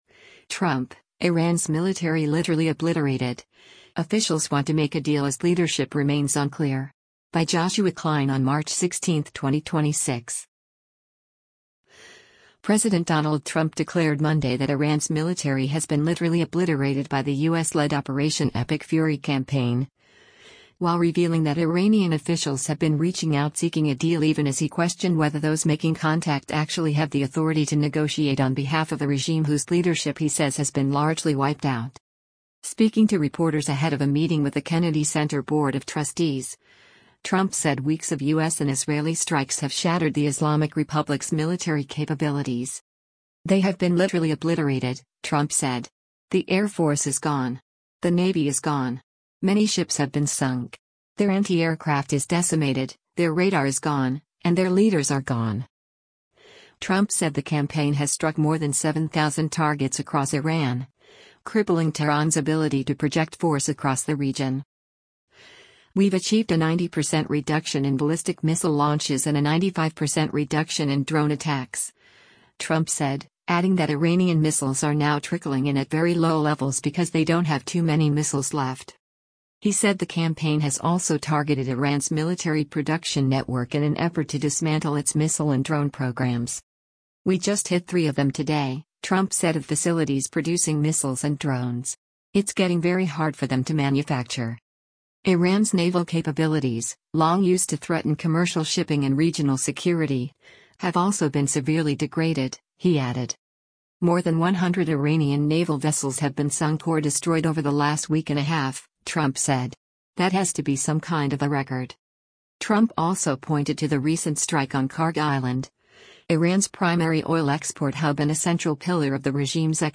Speaking to reporters ahead of a meeting with the Kennedy Center Board of Trustees, Trump said weeks of U.S. and Israeli strikes have shattered the Islamic Republic’s military capabilities.